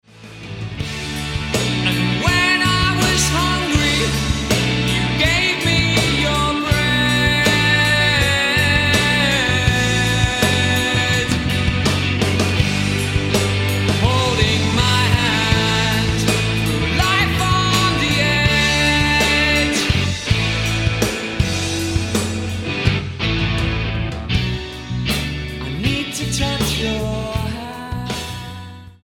grassroots rockers